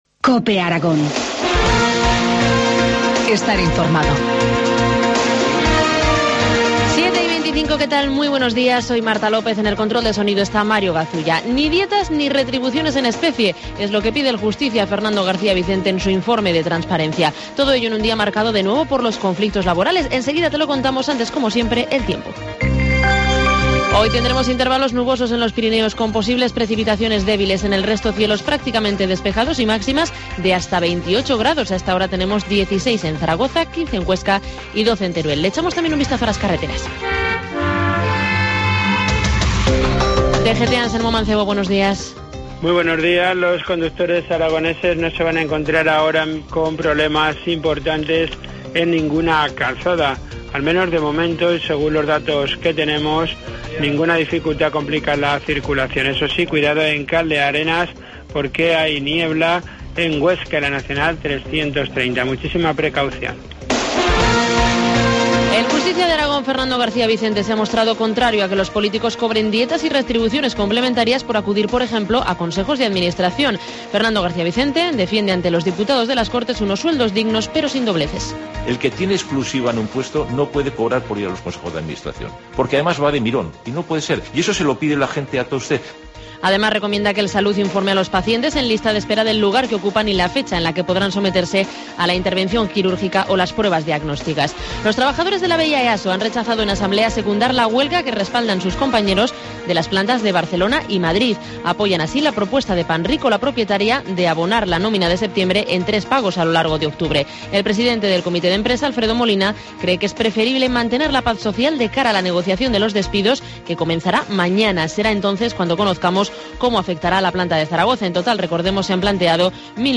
Informativo matinal, miércoles 16 de octubre, 7.25 horas